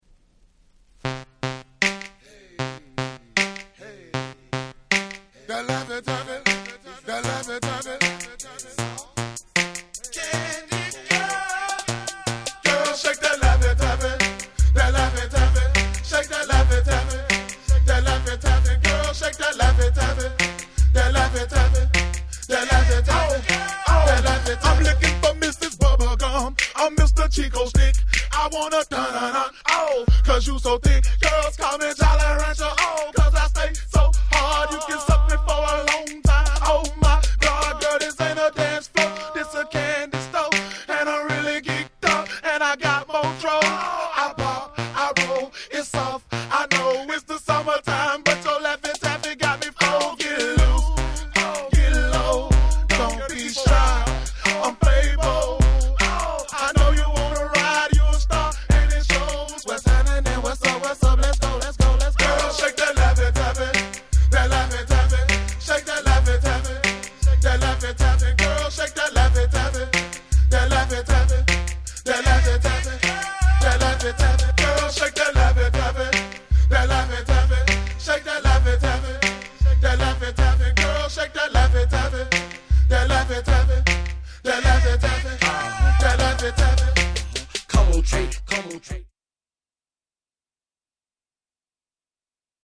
大Hit South !!
この「スカスカ」感が何とも言えなかったんですよね。。